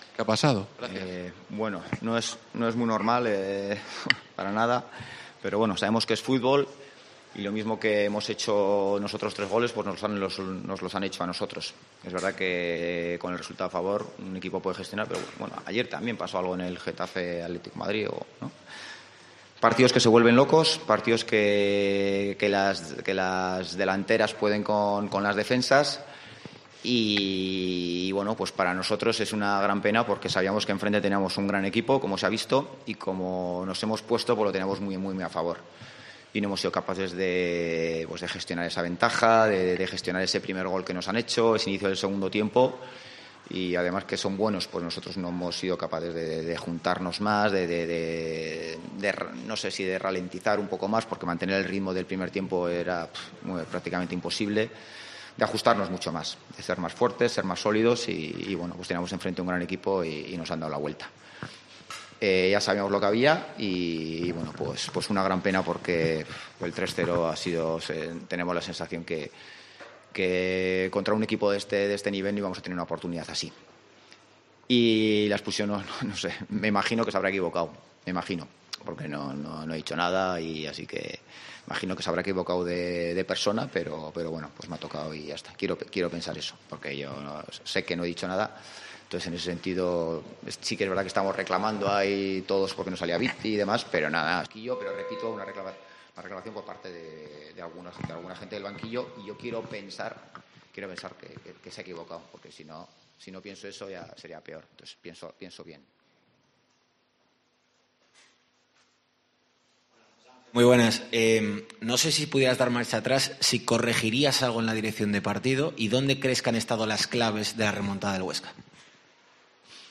Rueda de prensa Ziganda (post Huesca)